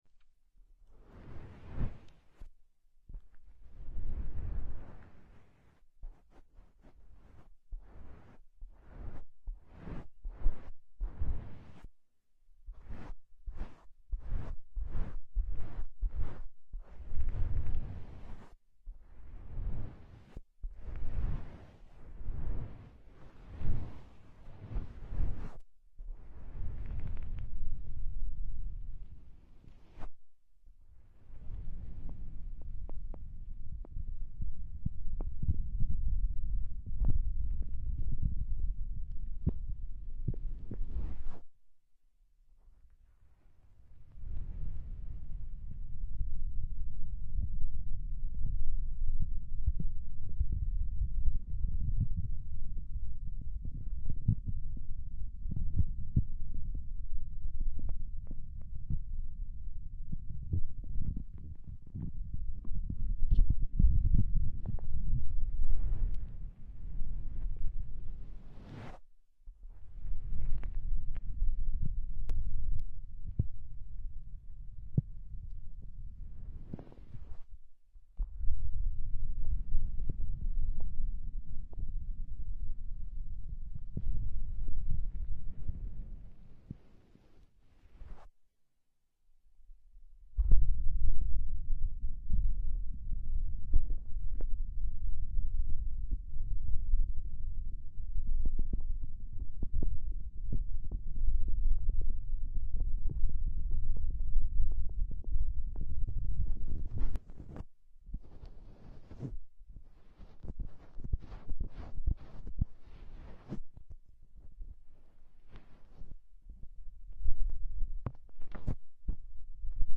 Asmr Rub The Eardrum Strong Sound Effects Free Download